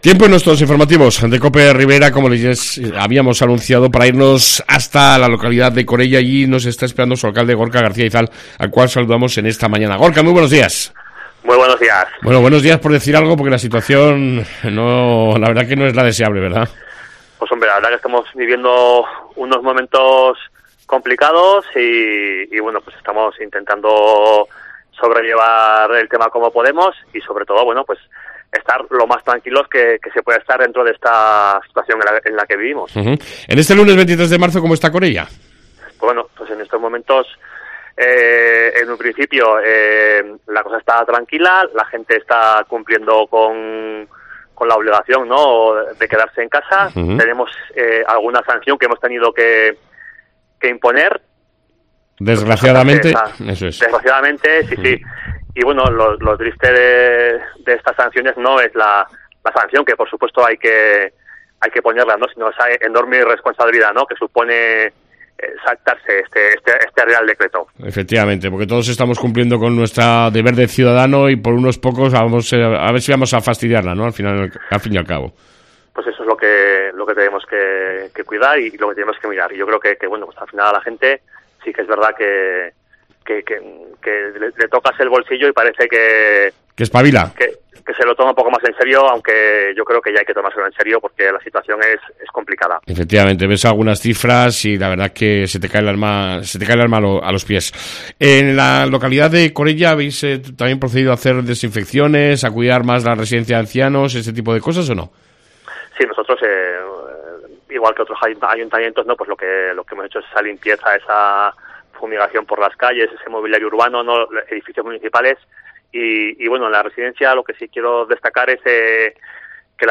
AUDIO: Entrevista con el Alcalde de Corella Gorka García Izal